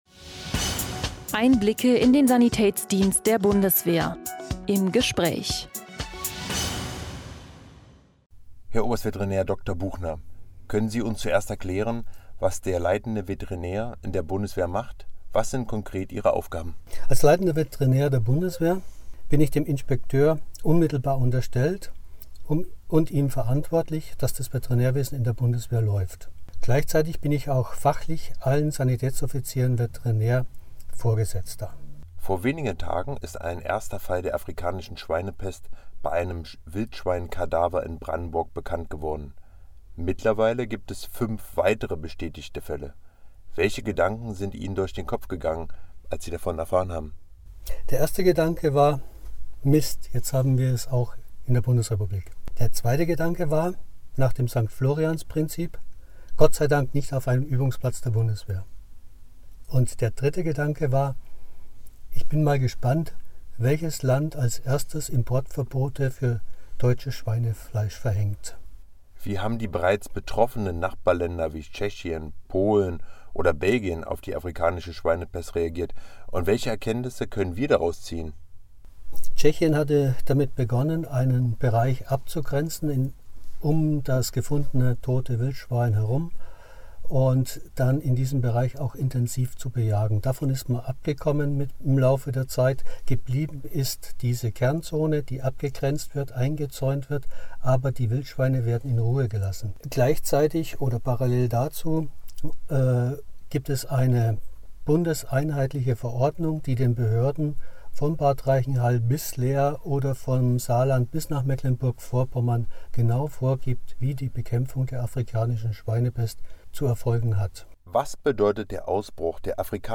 In einem aktuellen Audio-Interview ordnet der Veterinärmediziner die neue Situation für uns ein.